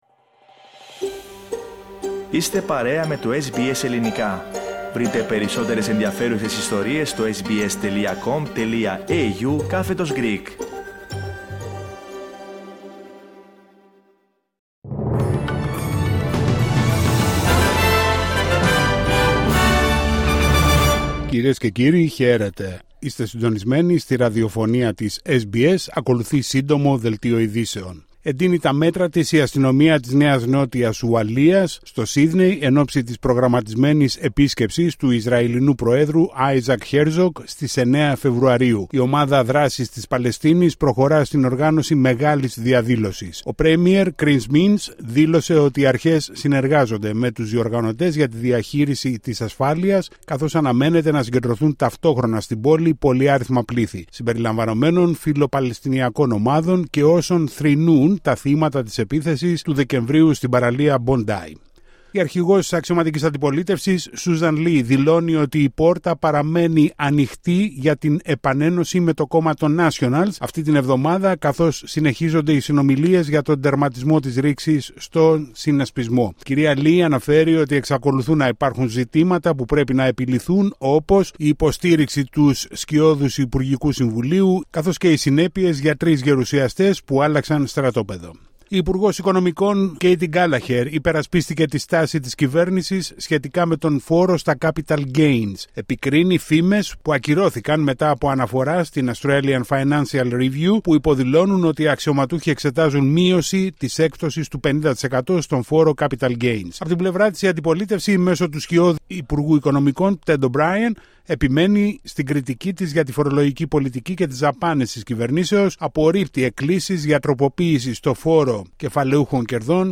Σύντομο δελτίο ειδήσεων στα Ελληνικά από την Αυστραλία την Ελλάδα την Κύπρο και όλο τον κόσμο